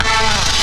68_03_stabhit-A.wav